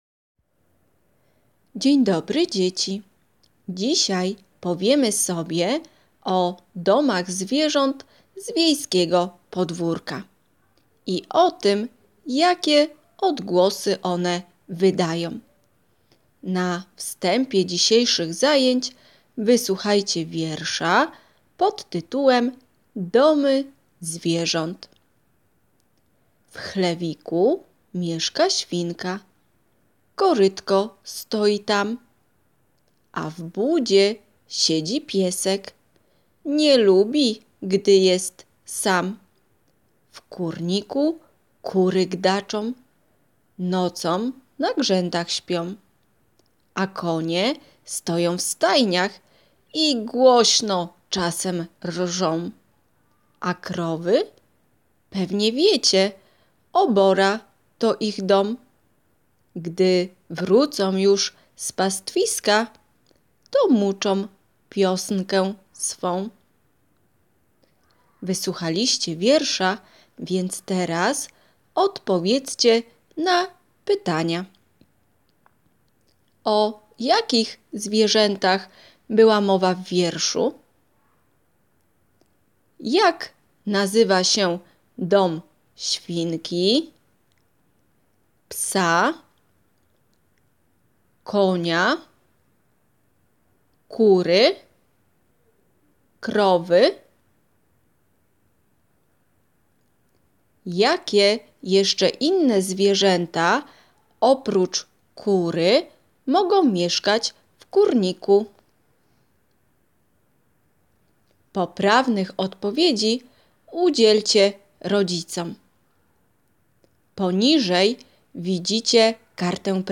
1. Słuchanie wiersza Iwony Fabiszewskiej Domy zwierząt.